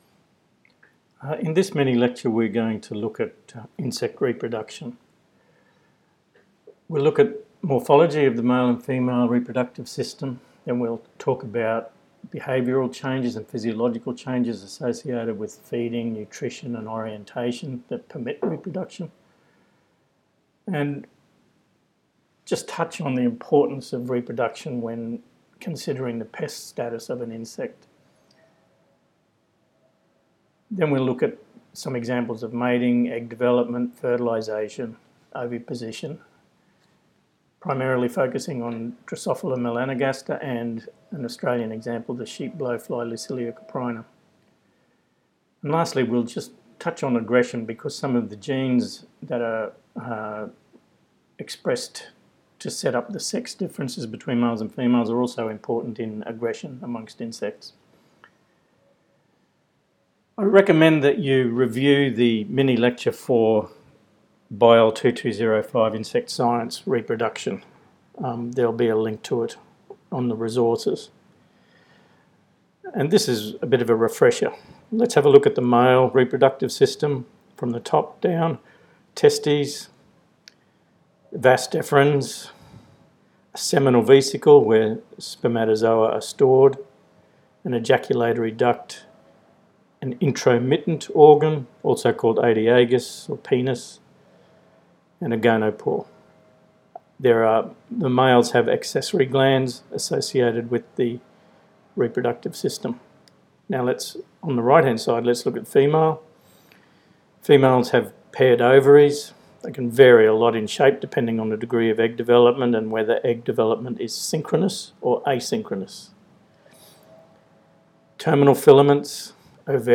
Mini-lecture: